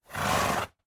sounds / mob / horse / idle1.mp3